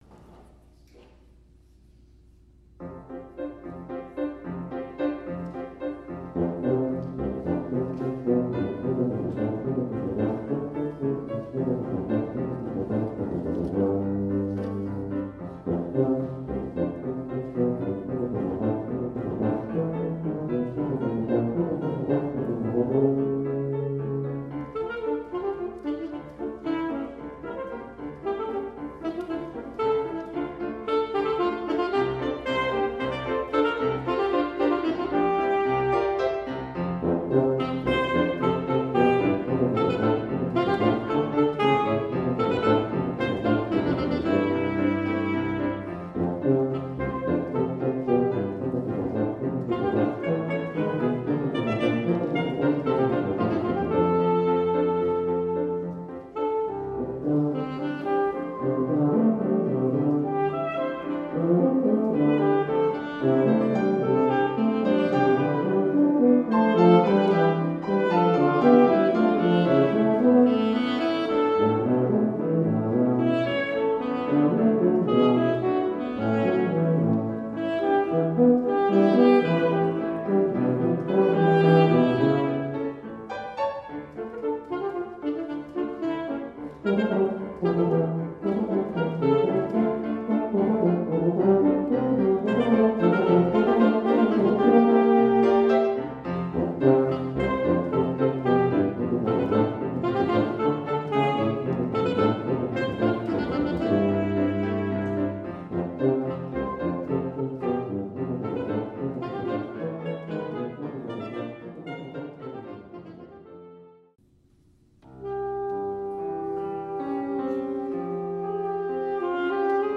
Arranger: AltoSax / Tuba / Piano
Voicing: Mixed Ensemble